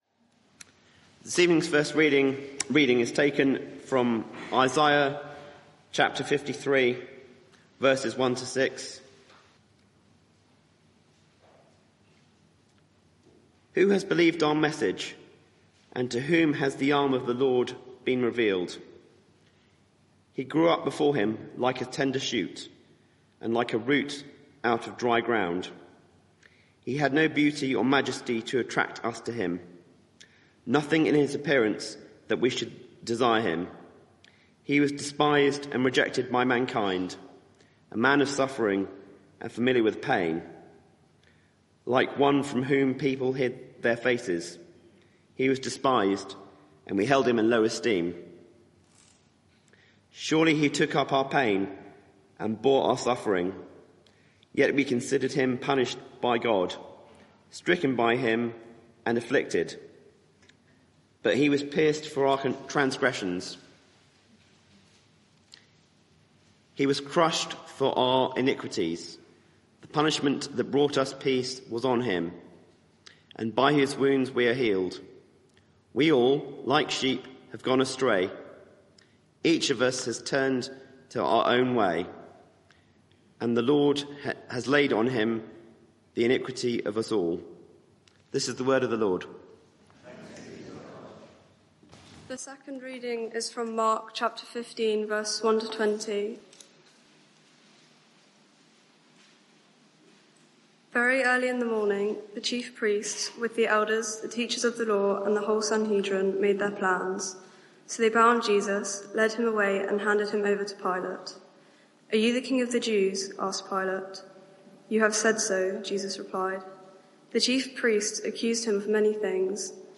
Media for 6:30pm Service on Sun 06th Apr 2025 18:30 Speaker
Sermon (audio) Search the media library There are recordings here going back several years.